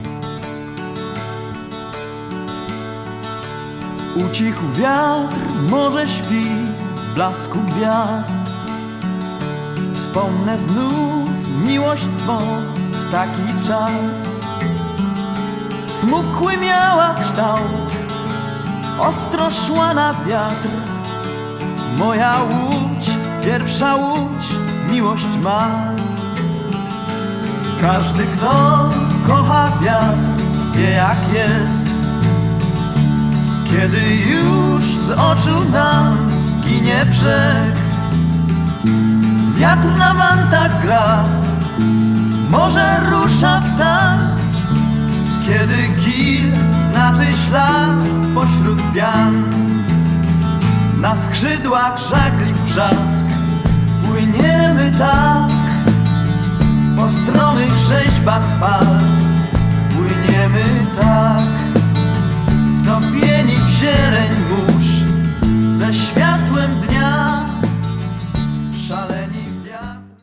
VOC GUITAR KEYB BASS DRUMS TEKST